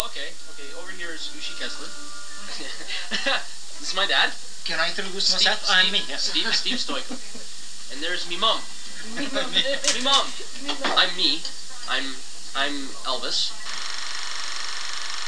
(Do I detect a certain Irish influence? LOL!) I love the way he says his last name.